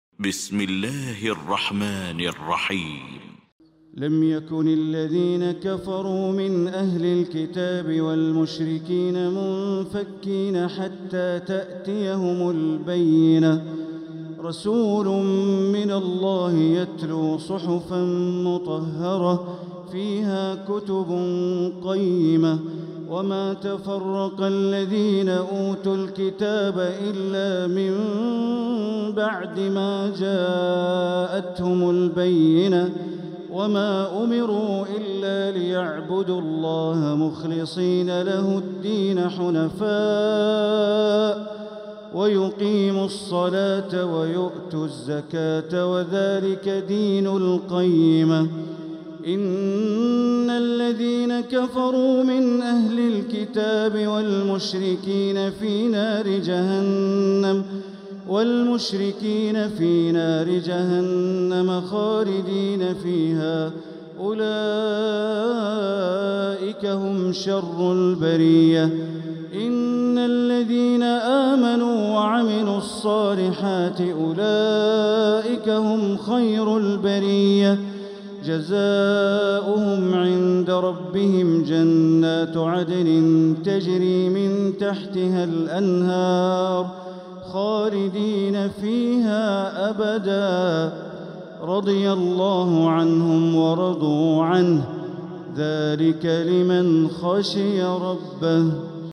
سورة البينة Surat Al-Bayyinah > مصحف تراويح الحرم المكي عام 1446هـ > المصحف - تلاوات الحرمين